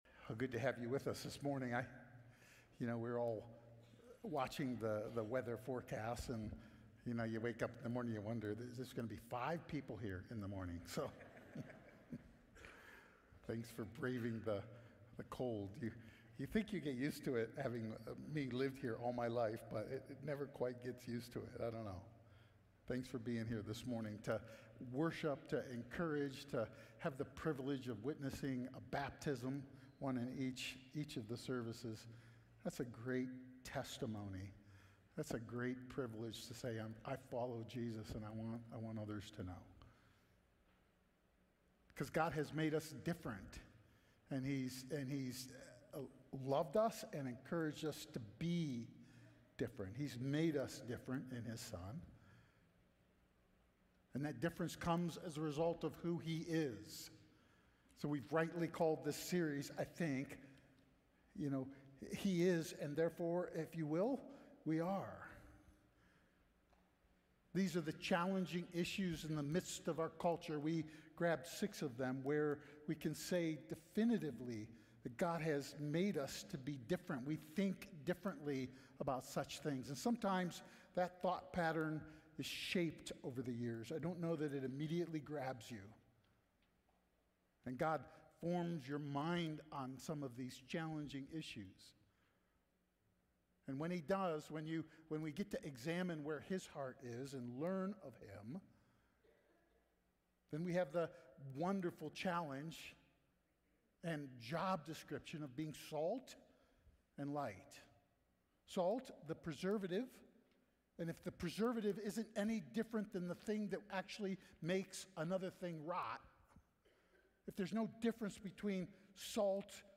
We are Audio File Sermon Notes More From This Series All We Need 2025-02-16 His Covenant 2025-02-09 His Redemption 2025-02-02 His Design 2025-01-26 His Creation 2025-01-12 His Words 2025-01-05